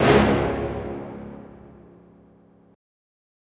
Hit (4).wav